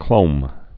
(klōm)